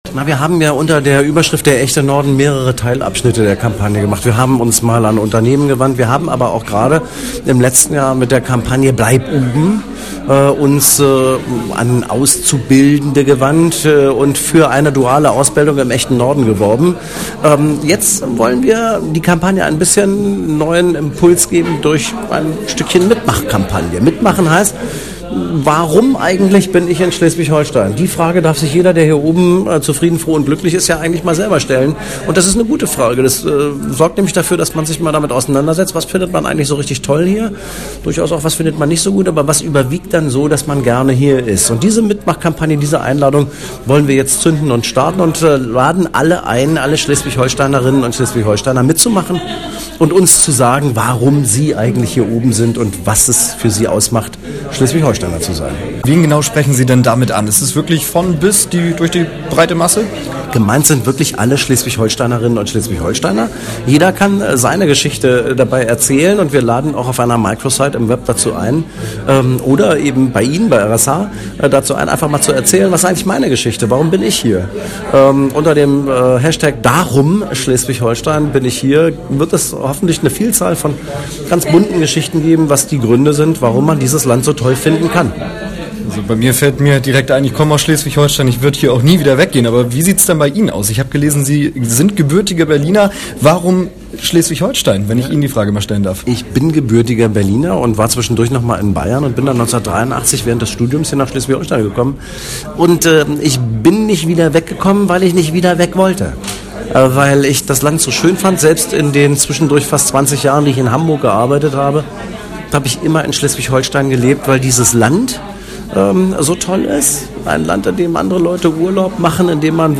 buIm Gespräch mit RS:H sagte Buchholz weiter zur Kampagne